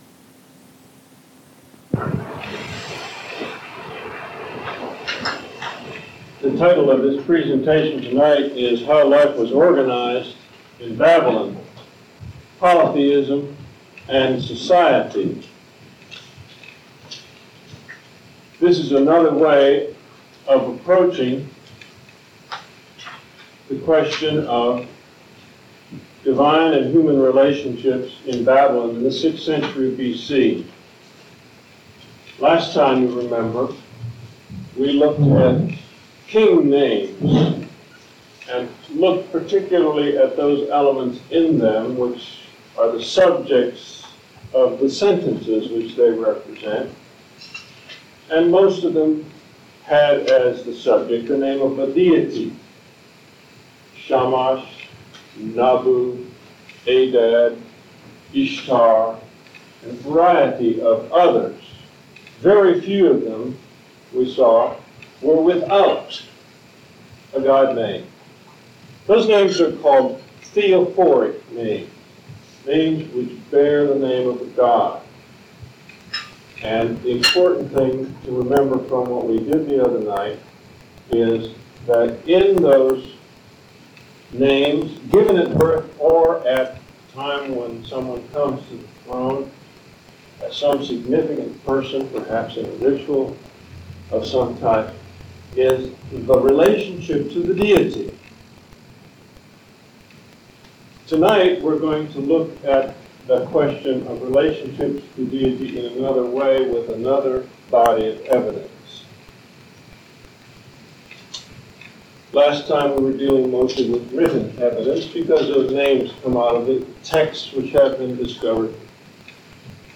An Archaeological Interpretation of Babylon in the 6th Century B. C.; Humans, Deities, and their relationships, lecture #3: How Life was Organized in Babylon: Polytheism and Society